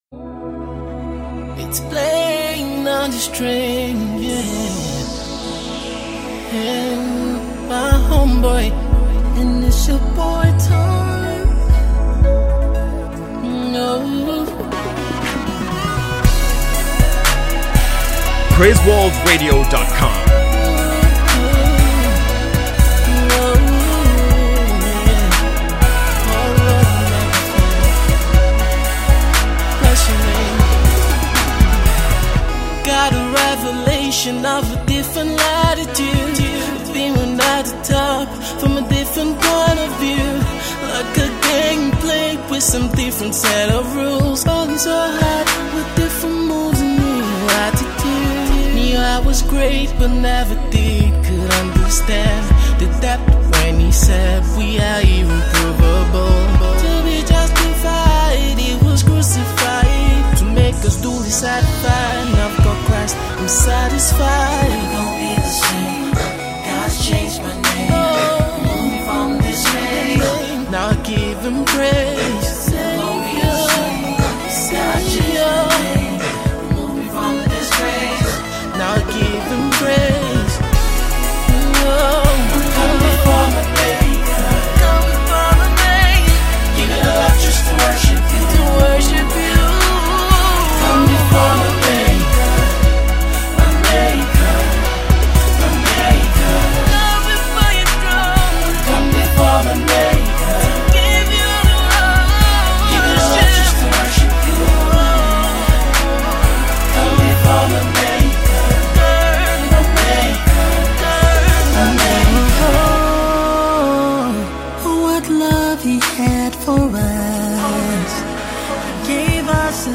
pure R&B single
This is not your regular worship tune.